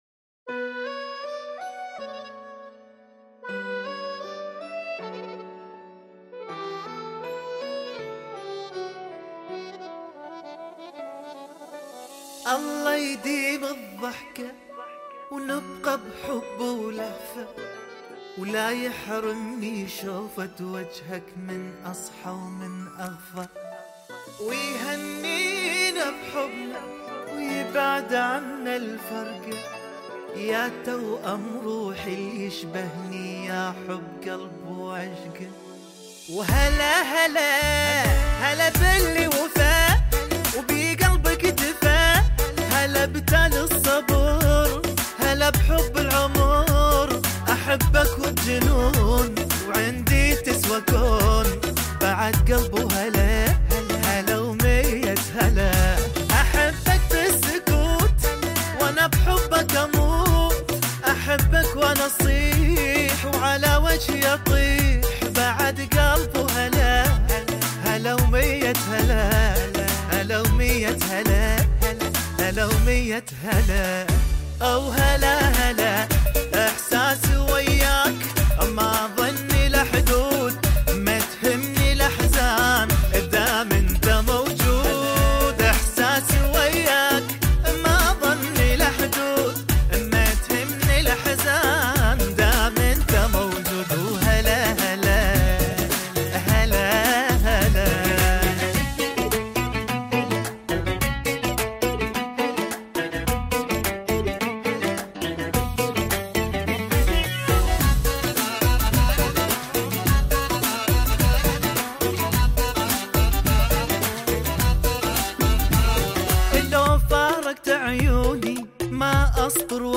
آهنگ عربی